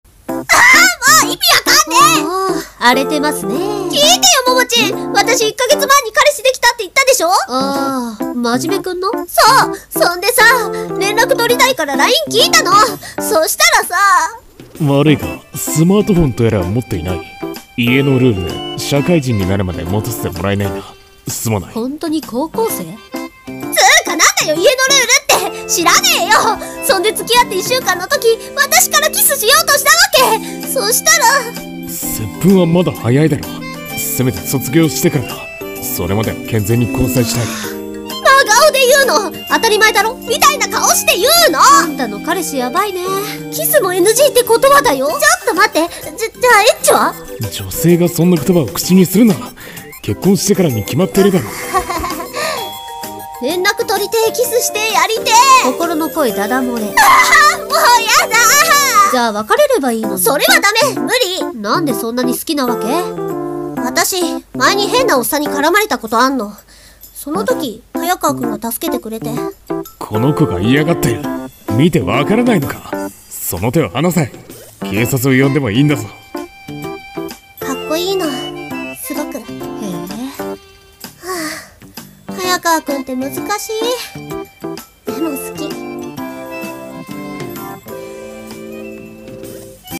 【三人声劇】